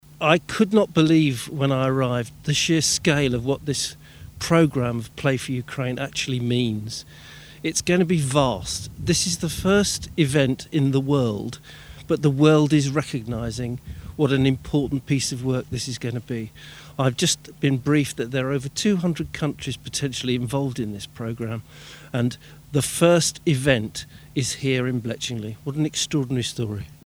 Chairman of Tandridge District Council, Chris Botten, watched the match together with relocated Ukrainian families, councillors and MPs: